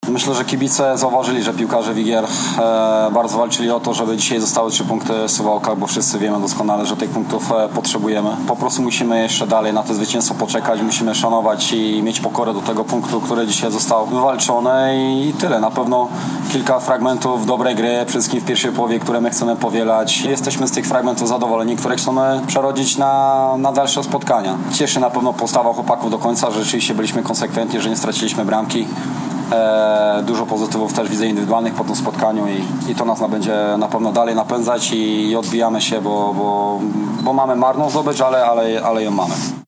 Na pomeczowej konferencji